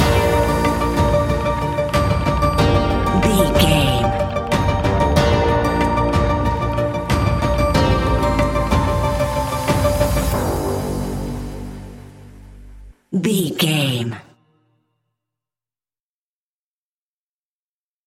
Aeolian/Minor
tension
ominous
dark
haunting
eerie
synthesiser
drums
strings
electronic music
Horror Synths